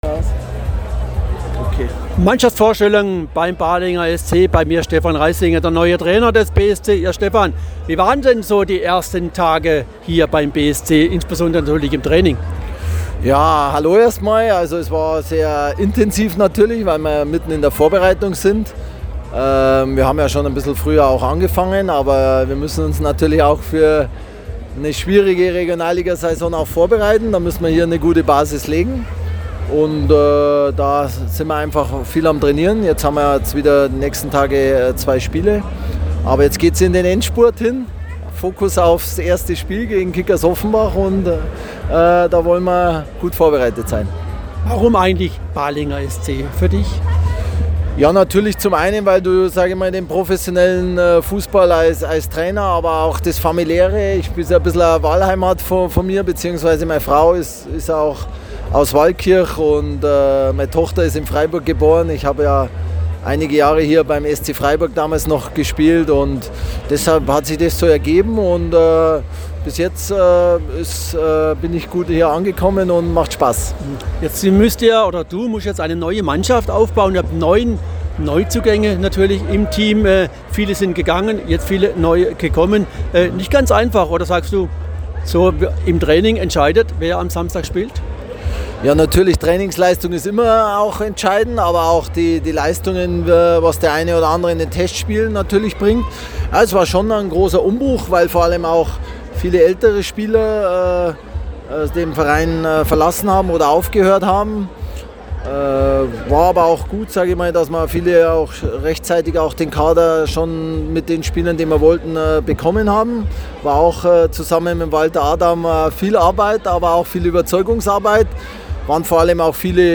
Mannschaftsvorstellung Bahlinger SC
Interview